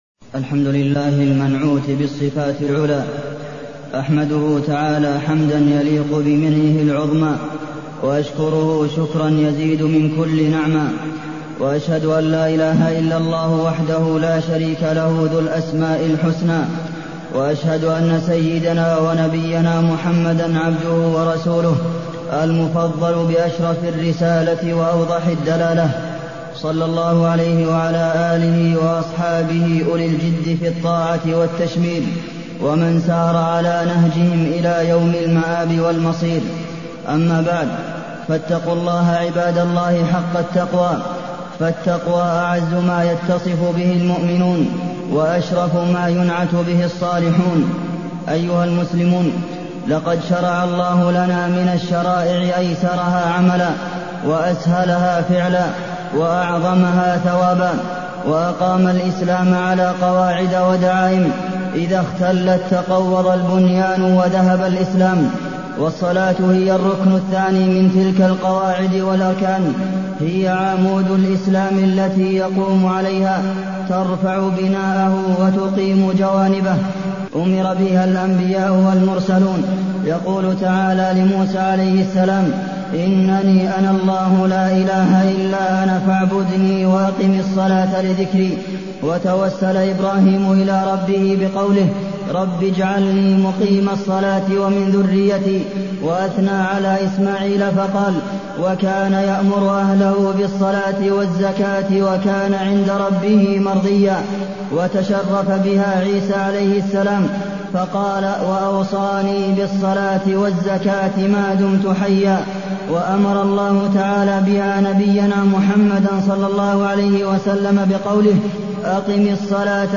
تاريخ النشر ٥ ربيع الثاني ١٤٢١ هـ المكان: المسجد النبوي الشيخ: فضيلة الشيخ د. عبدالمحسن بن محمد القاسم فضيلة الشيخ د. عبدالمحسن بن محمد القاسم الصلاة